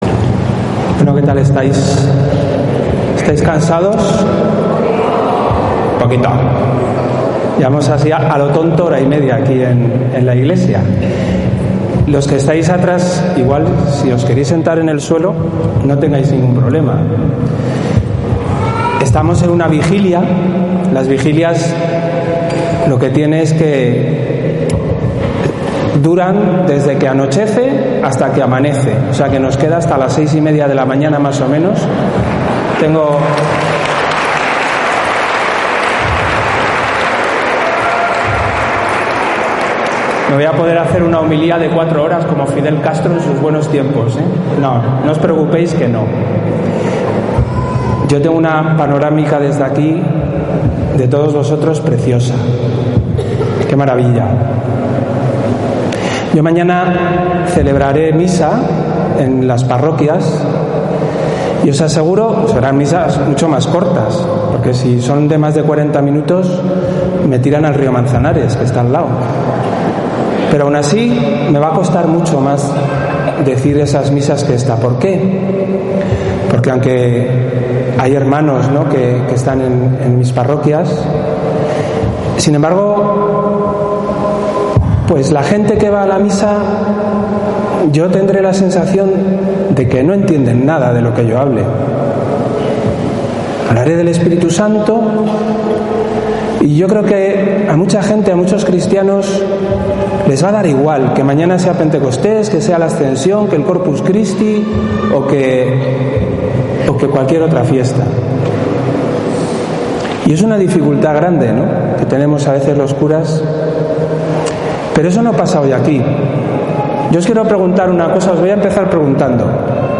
VIGILIA DE PENTECOSTES - 2019 (RCCE y RCCeE)